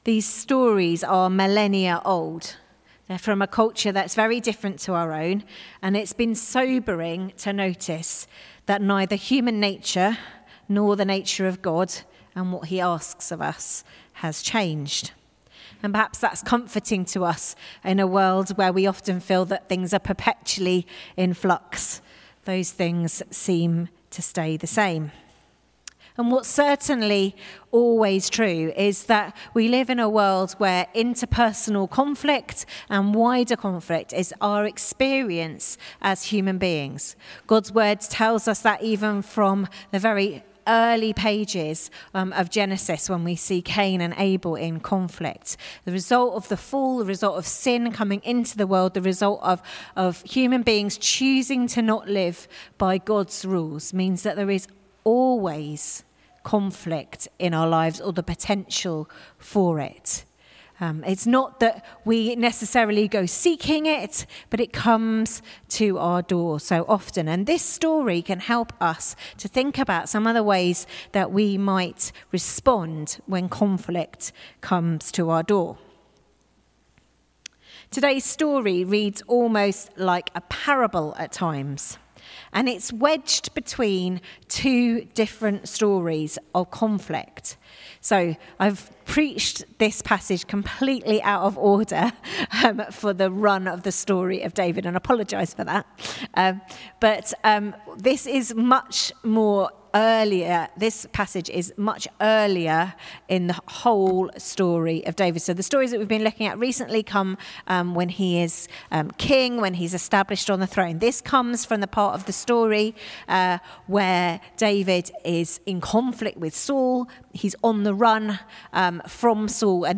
Sermon 20th July 2025